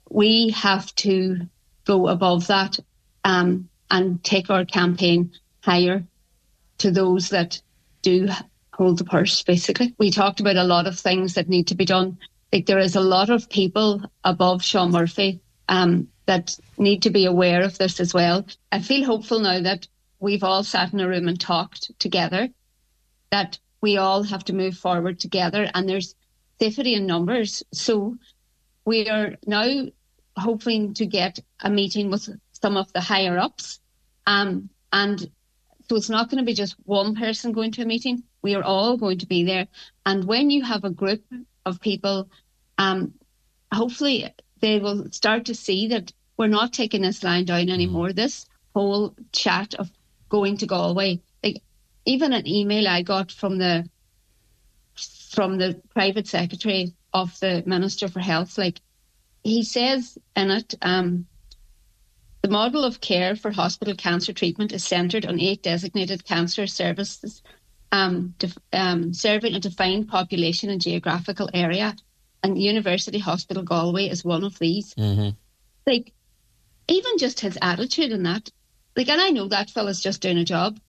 She told today’s Nine til Noon Show that those with the power to make a change need to open their eyes to the situation in Letterkenny: